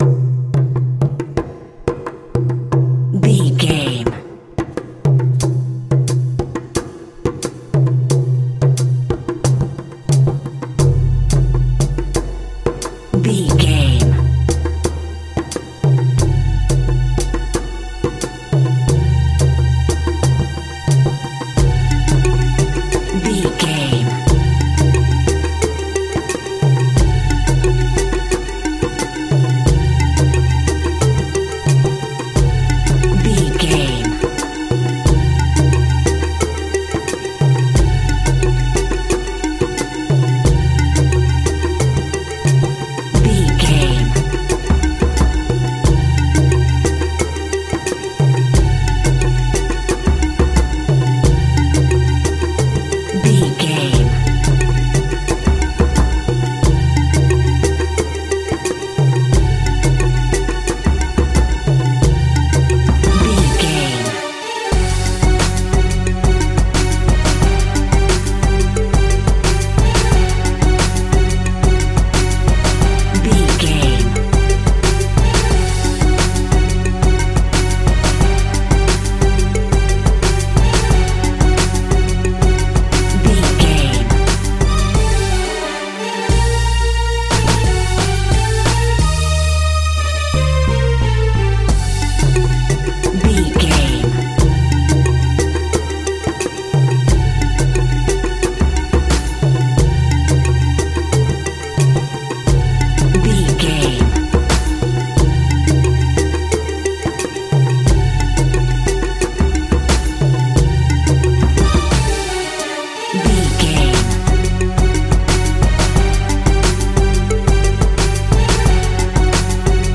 Aeolian/Minor
percussion